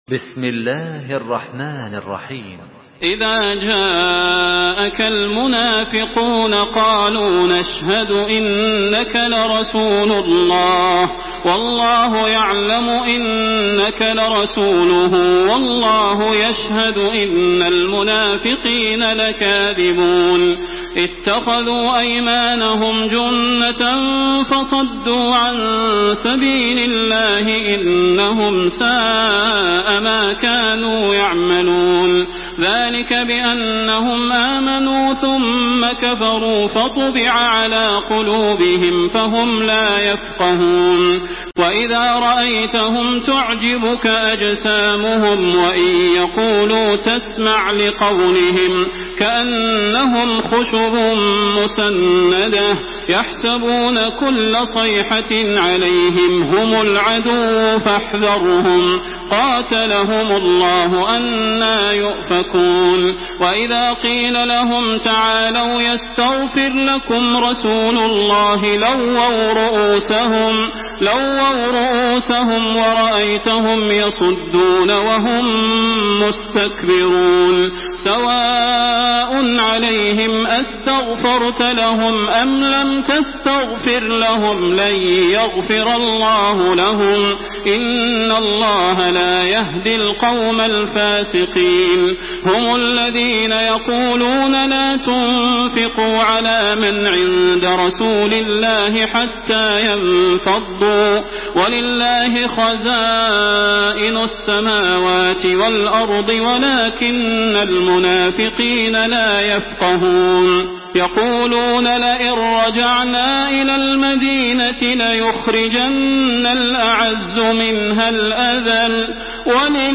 Makkah Taraweeh 1427
Murattal